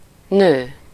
Ääntäminen
IPA: [fam]